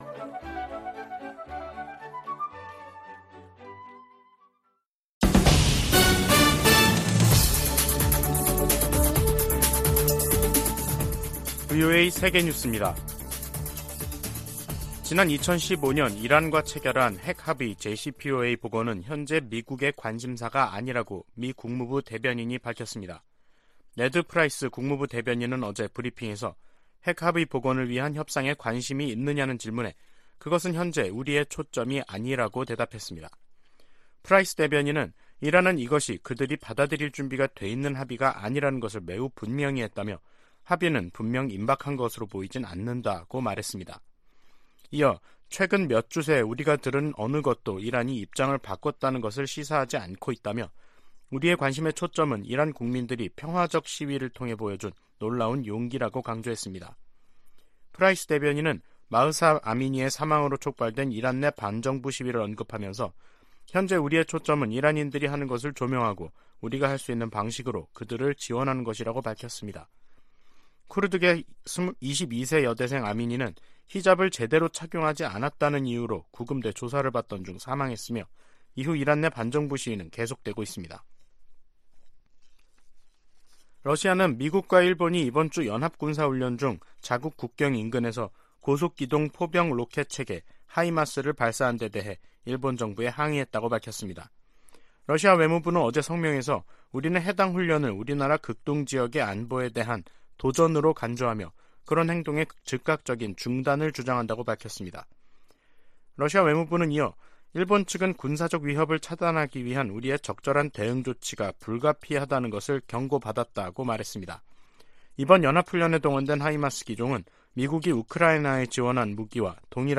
VOA 한국어 간판 뉴스 프로그램 '뉴스 투데이', 2022년 10월 13일 2부 방송입니다. 북한이 핵운용 장거리 순항 미사일을 시험발사했습니다.